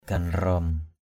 /ɡ͡ɣa-nrɔ:m/ (d.) một loại vương miện.